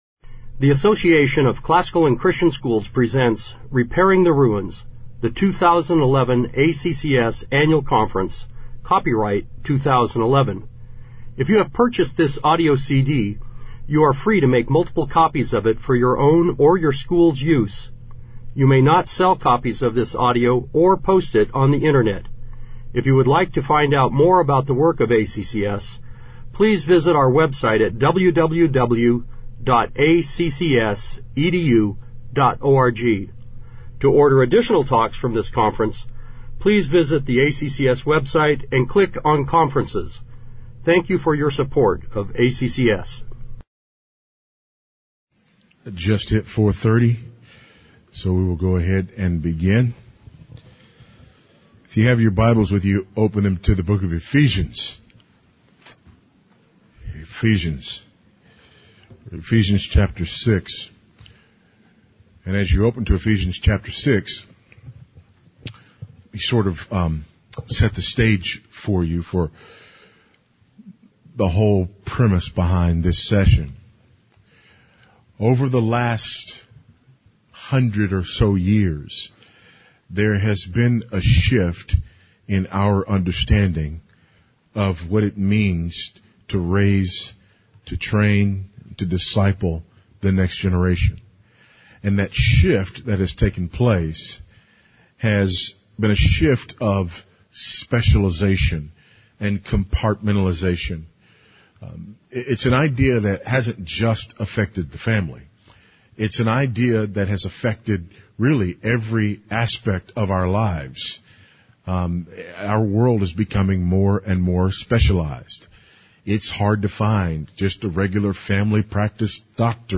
2011 Workshop Talk | 1:04:22 | All Grade Levels, Virtue, Character, Discipline
Speaker Additional Materials The Association of Classical & Christian Schools presents Repairing the Ruins, the ACCS annual conference, copyright ACCS.